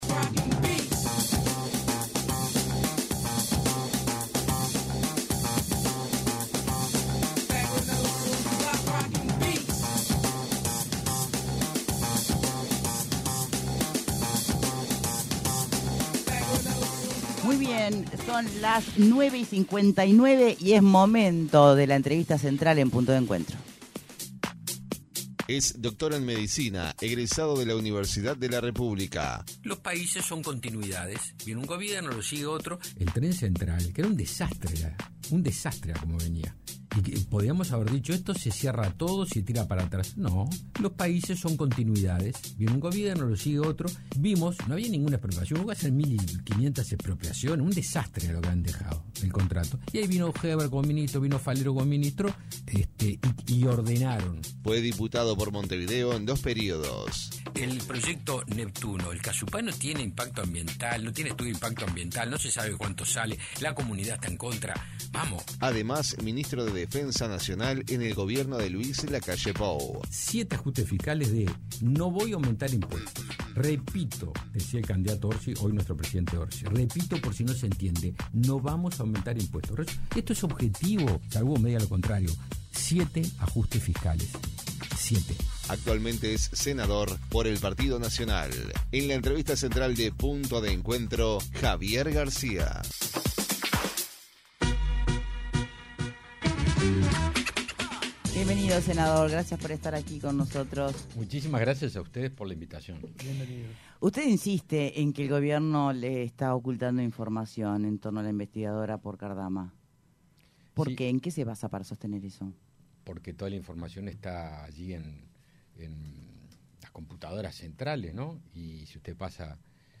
ENTREVISTA: JAVIER GARCÍA